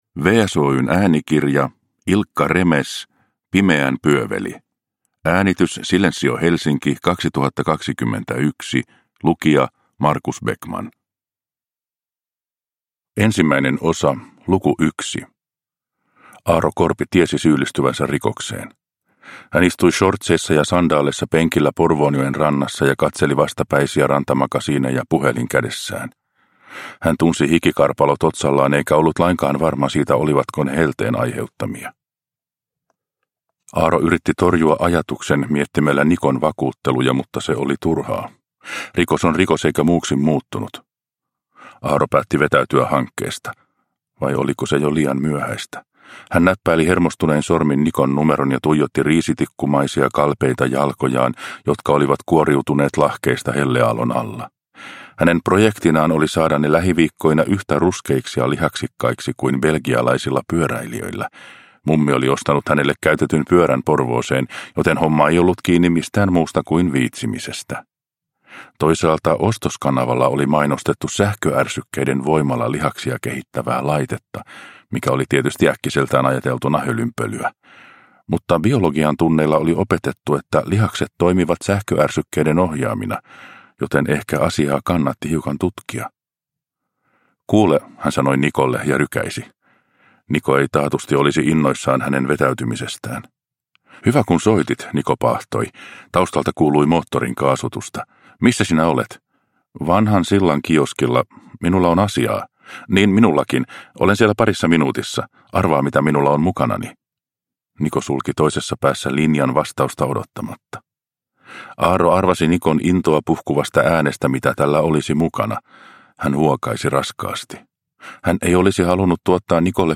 Pimeän pyöveli – Ljudbok – Laddas ner